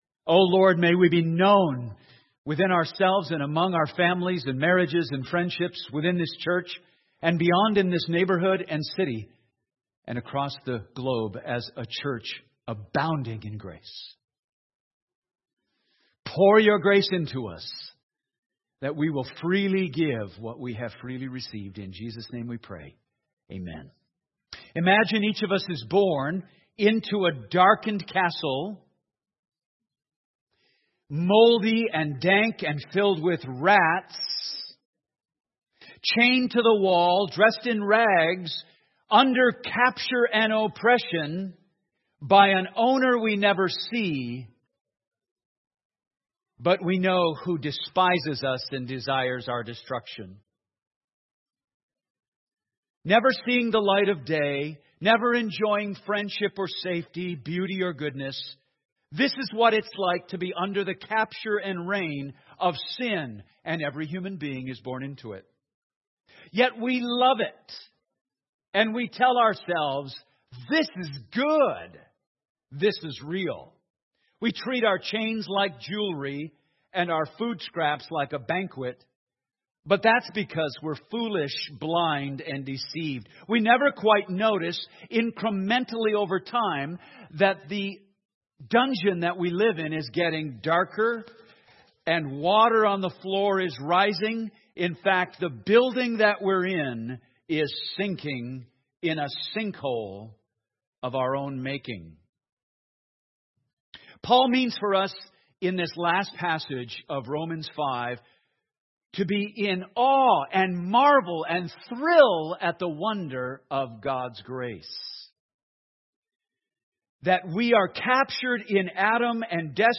Sermons | The Landing Church